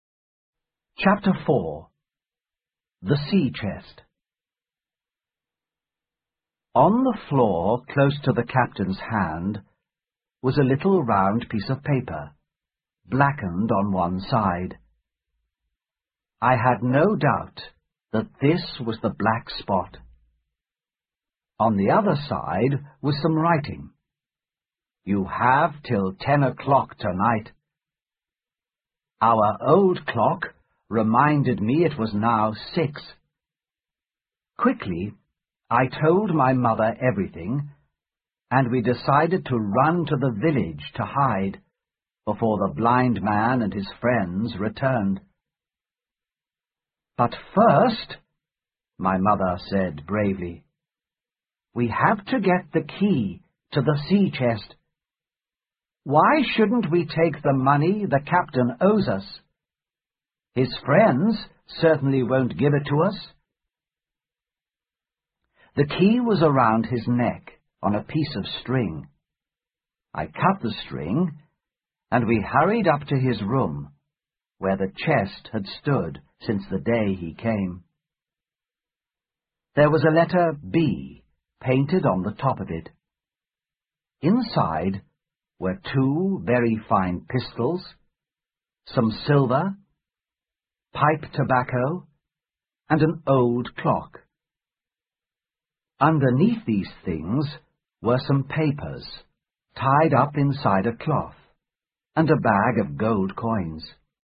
在线英语听力室《金银岛》第四章 水手箱(1)的听力文件下载,《金银岛》中英双语有声读物附MP3下载